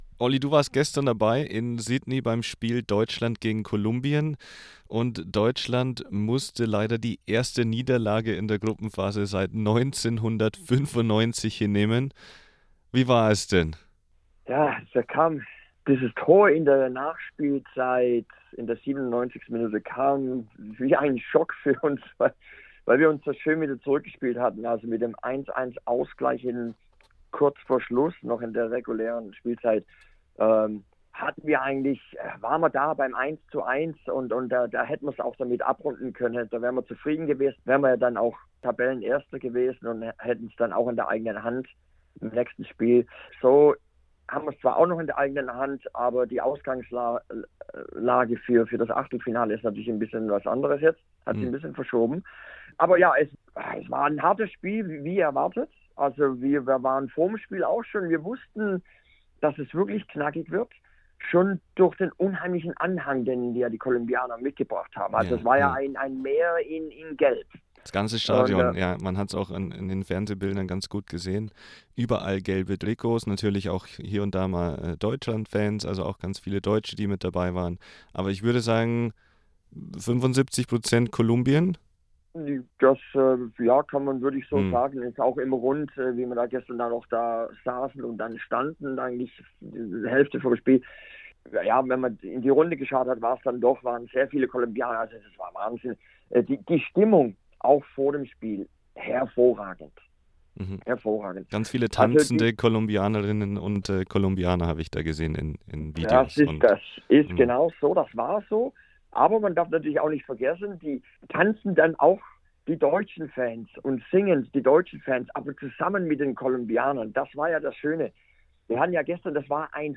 SBS talked to him about what happened on and off the pitch.